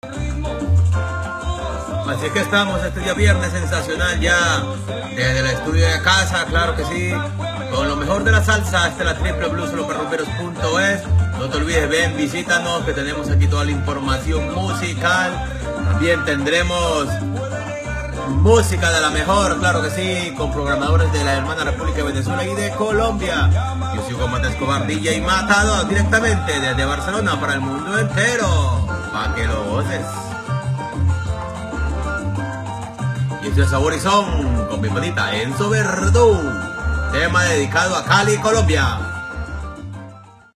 Musical
Identificicació de la ràdio per Internet i tema musical